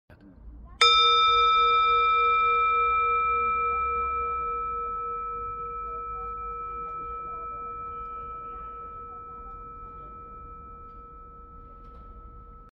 Within the dome of the Basilica, the sound carries prayers to the Light.